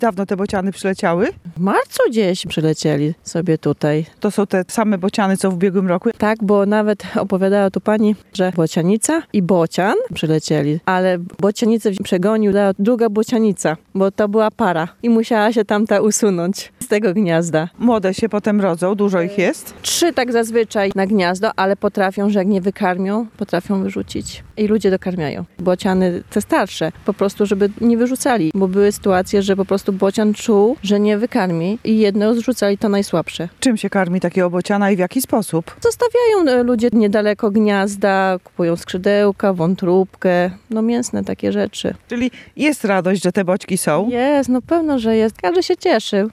We wsi Radruż w powiecie lubaczowskim, para bocianów ma gniazdo na słupie energetycznym niedaleko zabytkowej XVI-wiecznej cerkwi wpisanej na Listę Światowego Dziedzictwa UNESCO.
lok-bociany-na-gniezdzie.mp3